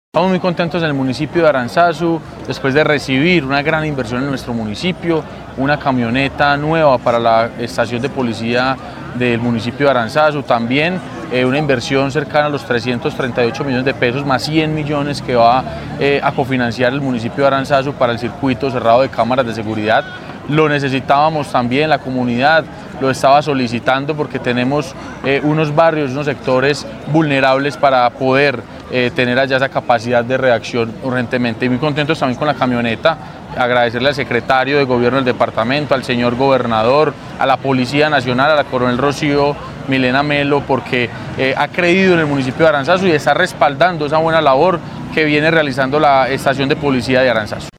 Sebastián Merchán Zuluaga, alcalde de Aranzazu.
Sebastian-Merchan-Zuluaga-alcalde-de-Aranzazu.mp3